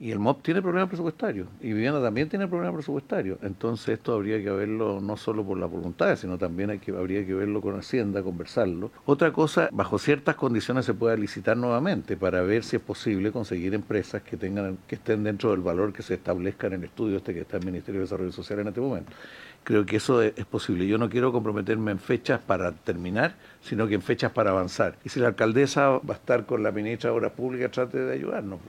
Sin embargo, y pese a las peticiones de ambas autoridades, el ministro Carlos Montes evitó entregar plazos para adjudicar el puente Cochrane, aludiendo a problemas presupuestarios que tienen que ser discutidos en el Ministerio de Hacienda.